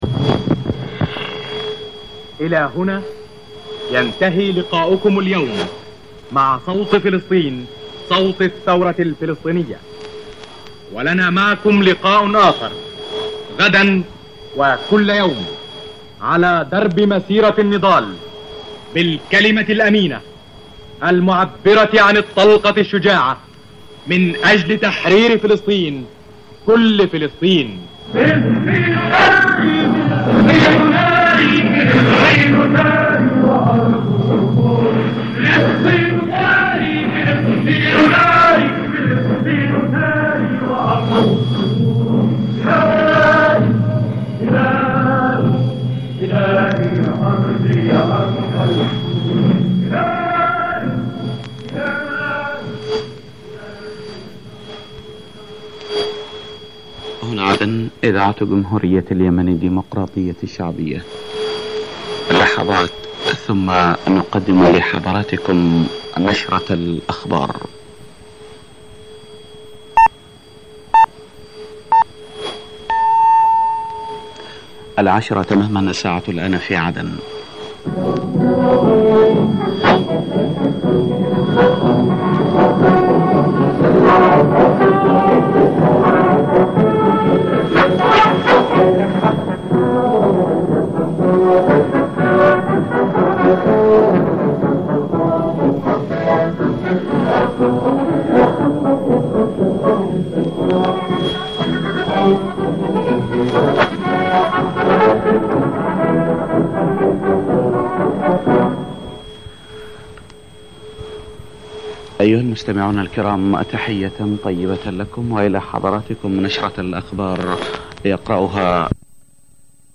短波放送の録音をMP3でアップしました。
古いニューステーマ曲と76年当時の国歌 明け方、よくこれを聴いてから寝ていた。